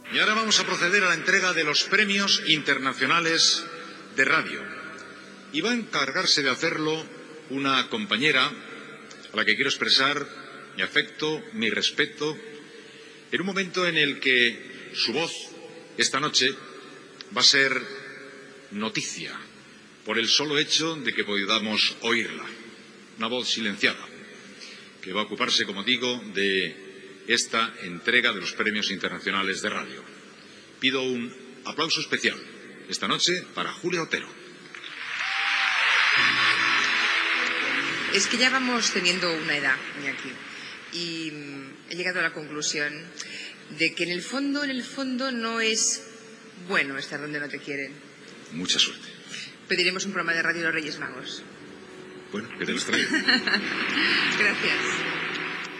Transmissió des del Palau Nacional de Barcelona. Iñaki Gabilondo dona pas a Júlia Otero per lliurar els Premis internacionals de ràdio
Entreteniment